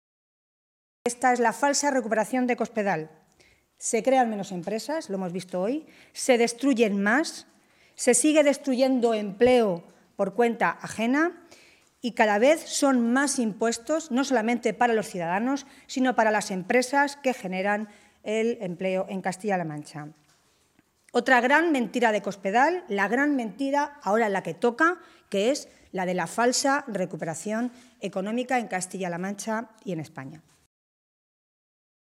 Milagros Tolón, portavoz de Empleo del Grupo Parlamentario Socialista
En una comparecencia ante los medios de comunicación esta mañana, en Toledo, Tolón echaba mano de las últimas estadísticas publicadas entre ayer y hoy por organismos oficiales como el Instituto de Crédito Oficial (ICO) o la Oficina Estadística de la Unión Europea (Eurostat), para avalar sus afirmaciones.
Cortes de audio de la rueda de prensa